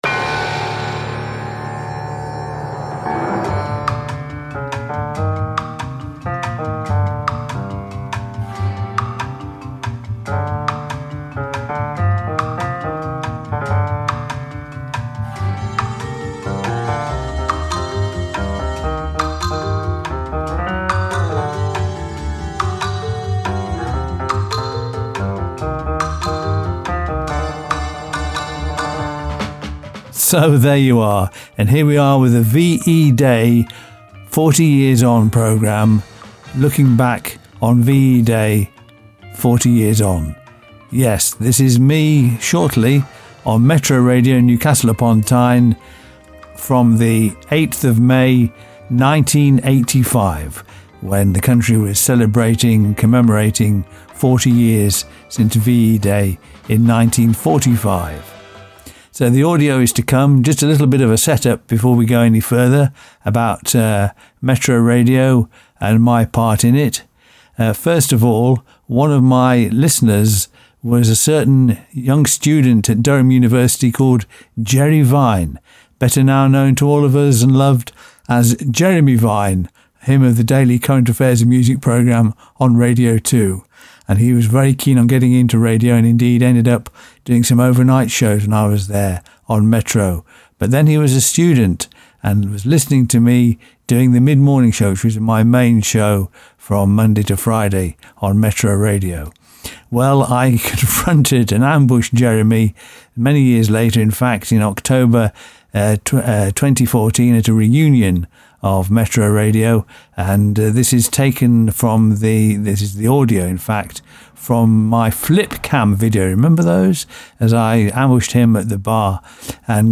This is how I, on an independent radio station in NE England, commemorated VE Day 40 years ago, about VE Day 40 years before!